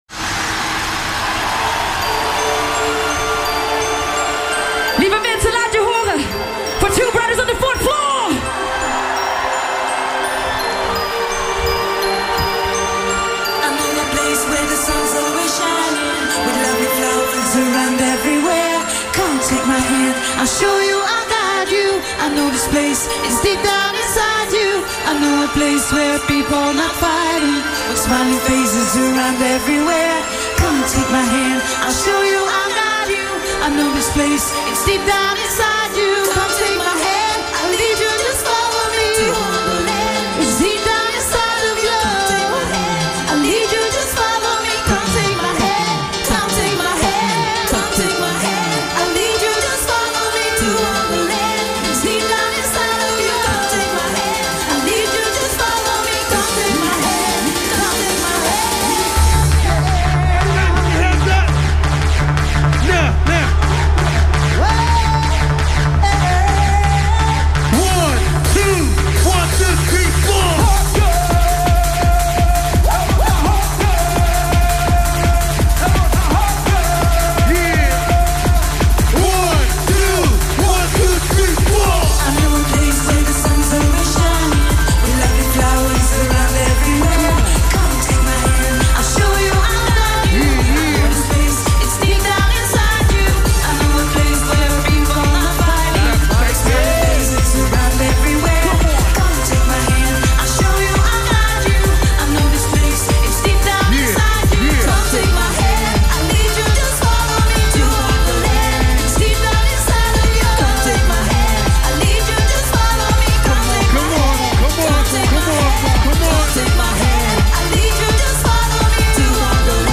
DJ Mixes and